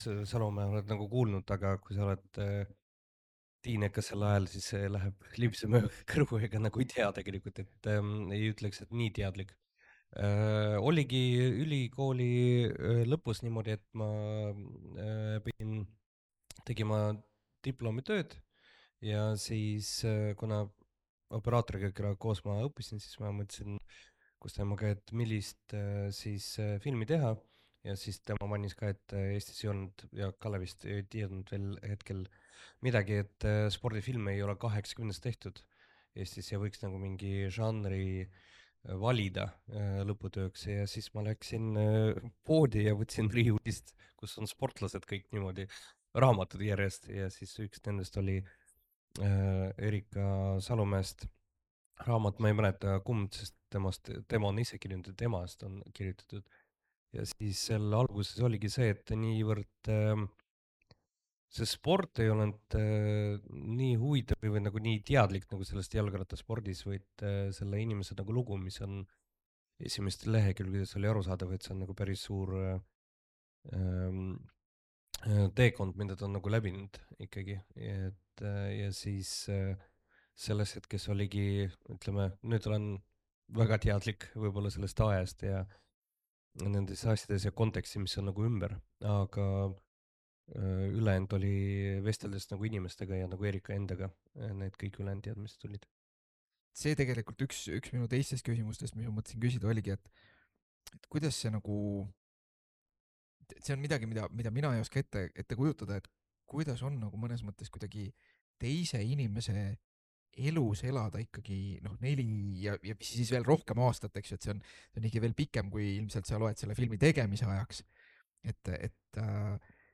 Vestluse algus jäi tehniliste viperuste tõttu salvestusest välja, mistõttu algab salvestus poole lause pealt – selleks ajaks oli juttu juba mõni minut aetud.